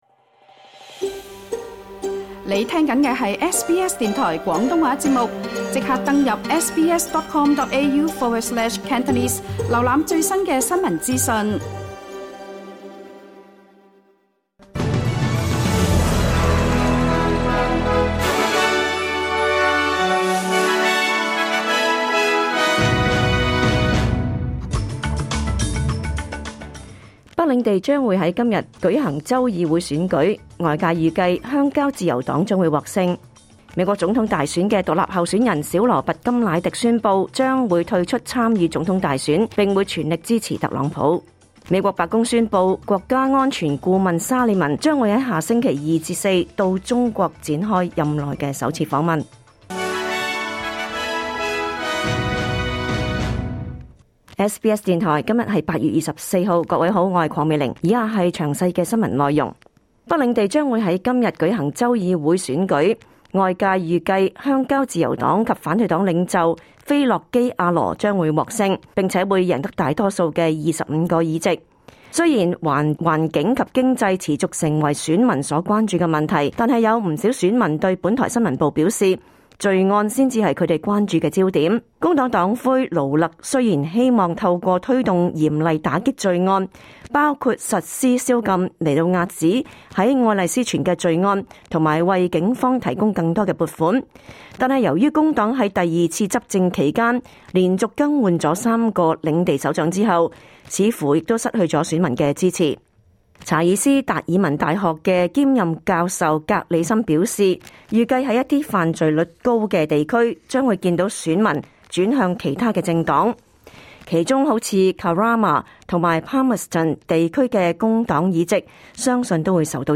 2024年8月24日SBS廣東話節目詳盡早晨新聞報道。